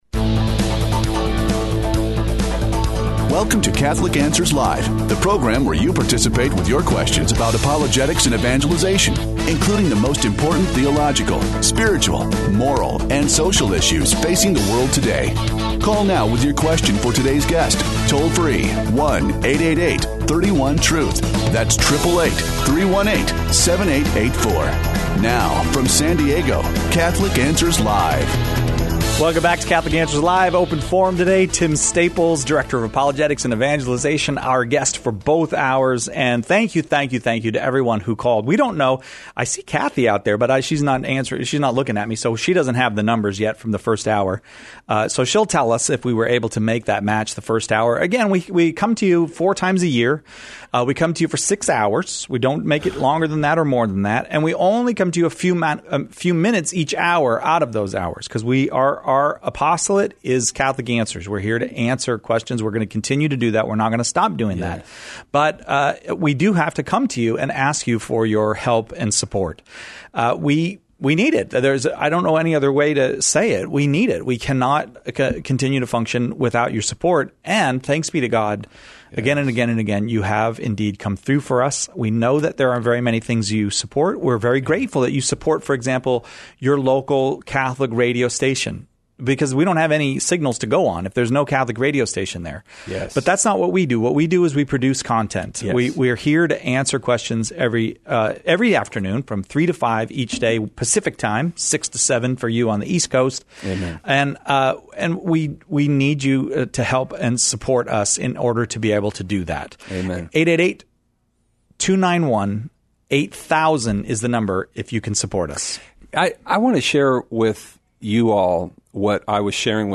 Open Forum